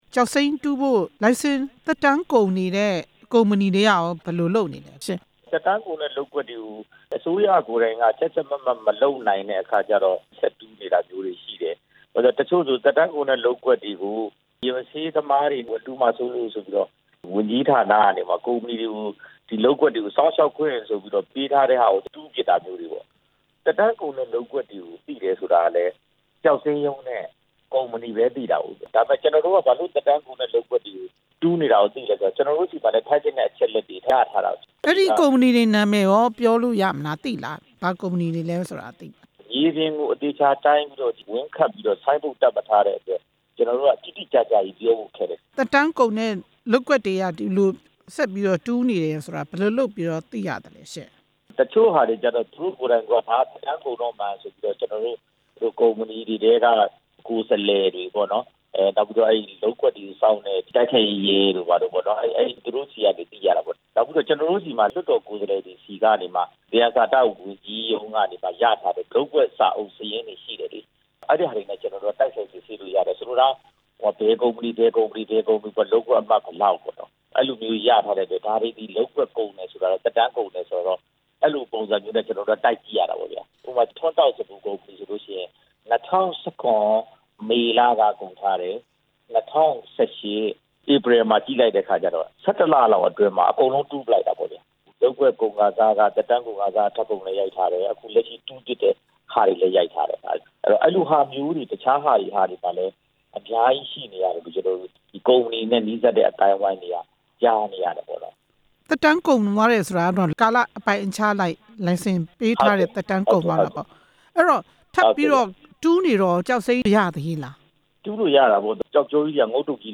သက်တမ်းကုန်နေတဲ့ကုမ္ပဏီတွေ ကျောက်စိမ်းတူးနေတဲ့အကြောင်း မေးမြန်းချက်